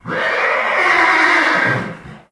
c_whorse_hit3.wav